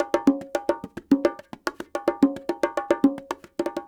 44 Bongo 15.wav